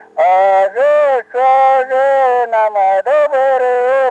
Произнесение слова что как шо
/у”-же шо ”-же на”м до”- бы-рыым/